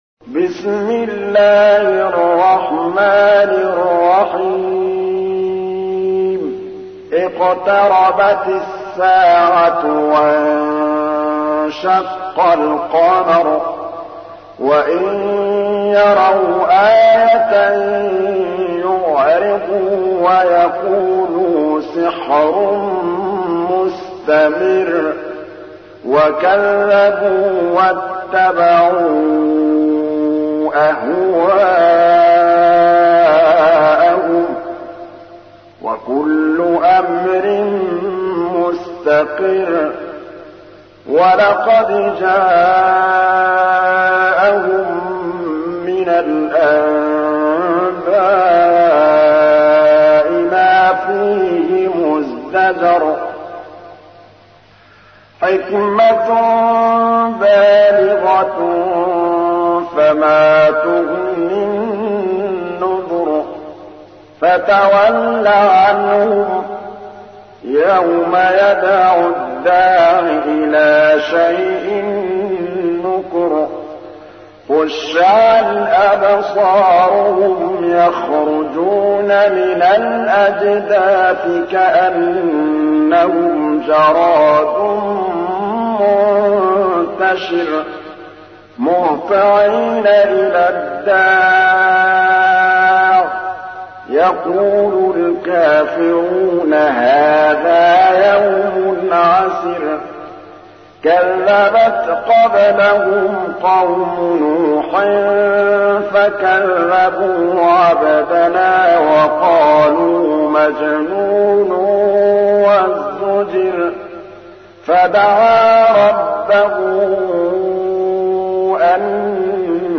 تحميل : 54. سورة القمر / القارئ محمود الطبلاوي / القرآن الكريم / موقع يا حسين